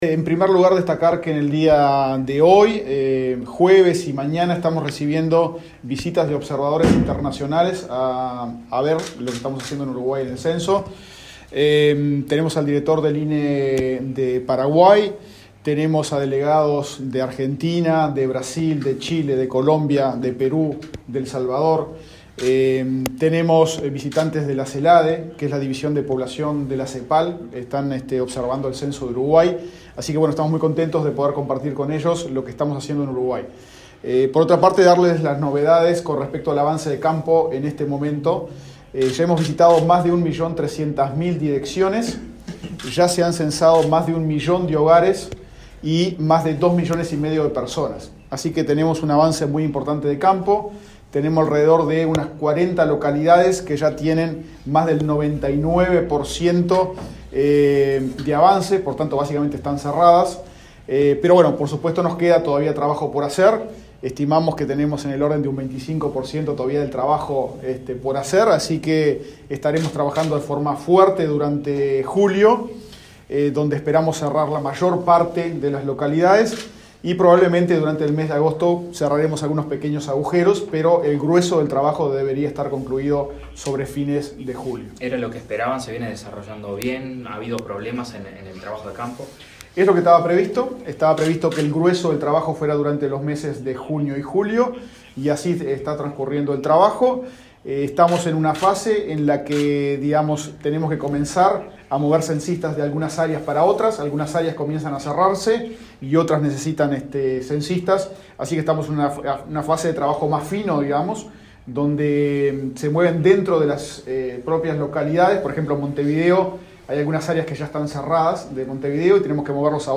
Declaraciones del director del INE, Diego Aboal
Declaraciones del director del INE, Diego Aboal 07/07/2023 Compartir Facebook X Copiar enlace WhatsApp LinkedIn El jueves 6 en la Torre Ejecutiva, el director del Instituto Nacional de Estadística (INE), Diego Aboal, informó a la prensa acerca de los avances del Censo 2023.